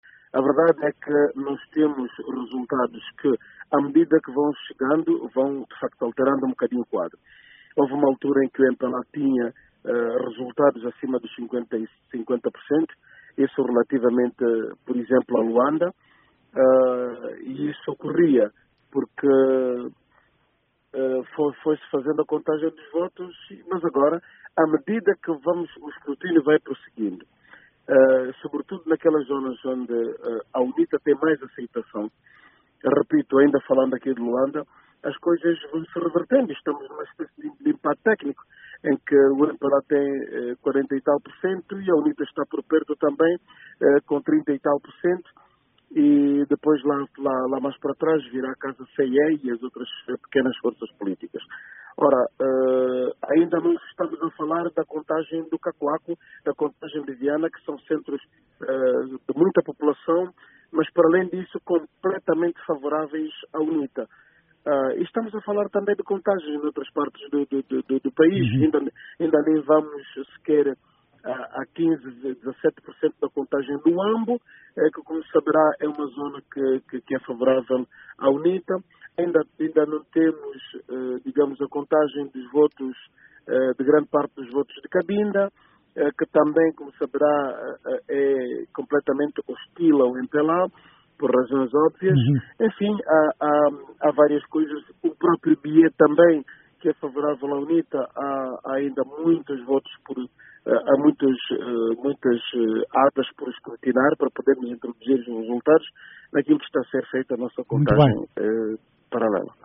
Numa anterior declaração à VOA, antes do anúncio dos resultados parciais divulgados pela CNE, o vice-presidente da UNITA, Raúl Danda, garantia não haver qualquer possibilidade de o MPLA obter uma maioria qualificada.